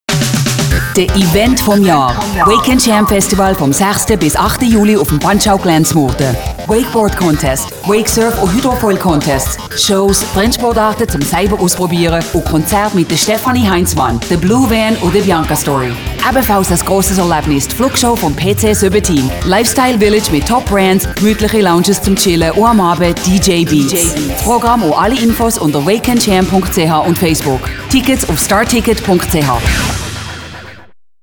freundlich, ehrlich, authentisch, sinnlich, warm, variabel, seriös, sympatisch, wandlungsfähig, emotional
Sprechprobe: Sonstiges (Muttersprache):
conversational, friendly, real, soothing, educational, informative, warm